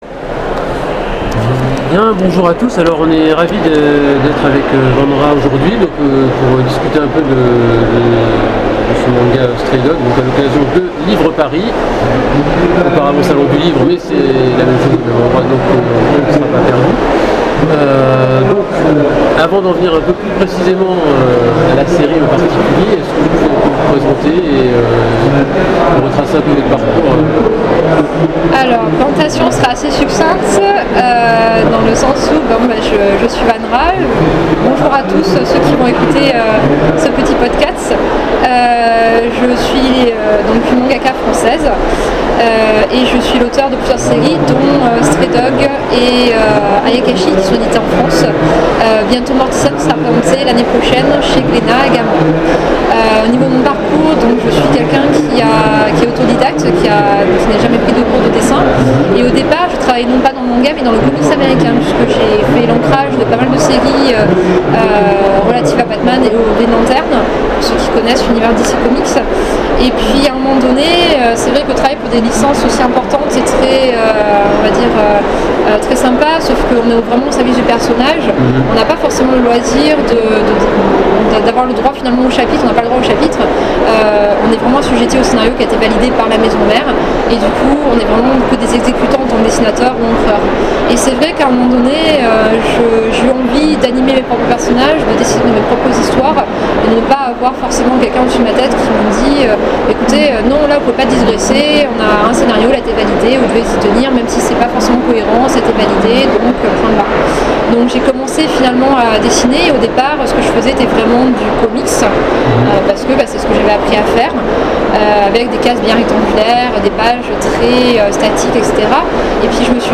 Interview 2017